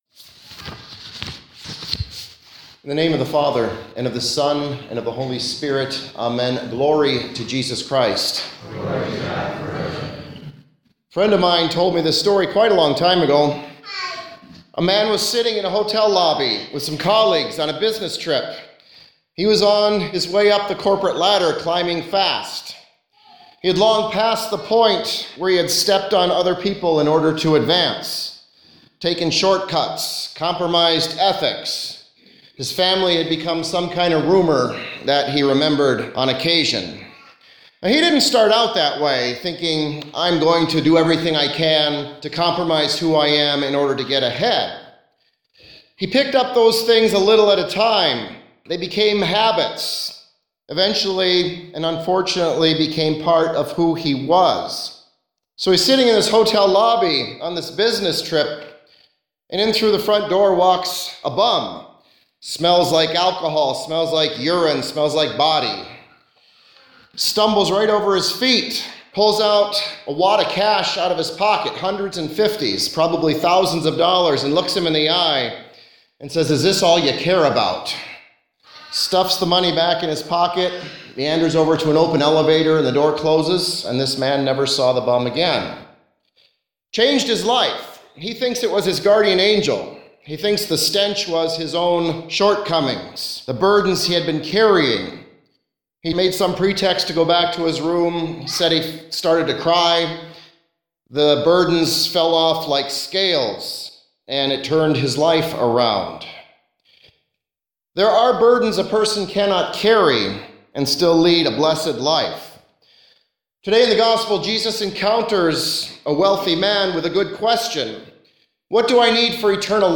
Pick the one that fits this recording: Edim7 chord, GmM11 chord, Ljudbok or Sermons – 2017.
Sermons – 2017